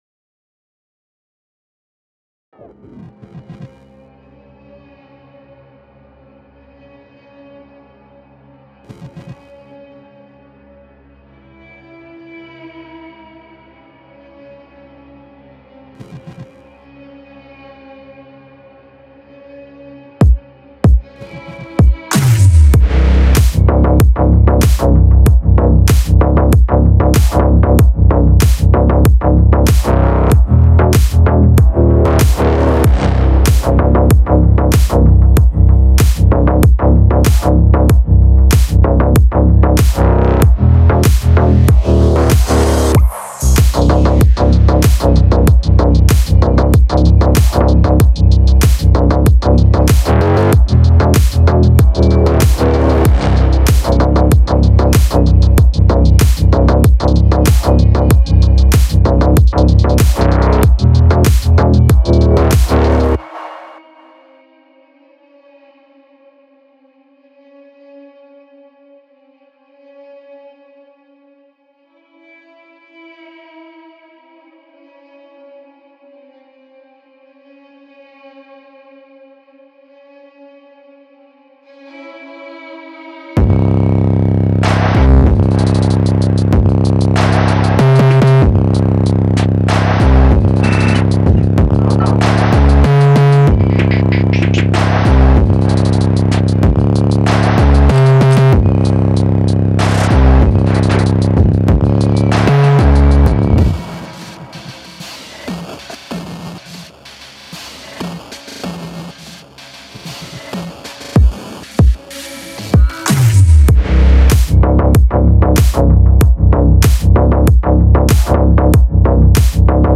отличается яркими гитарными рифами и выразительным вокалом